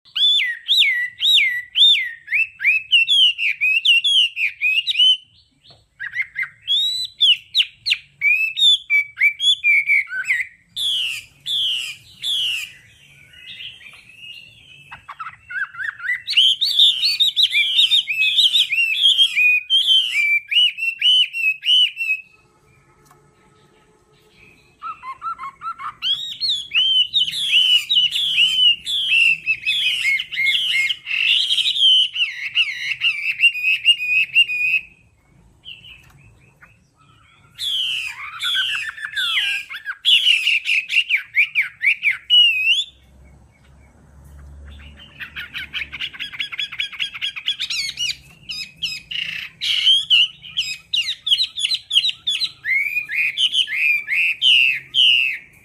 Tiếng Họa Mi hót mp3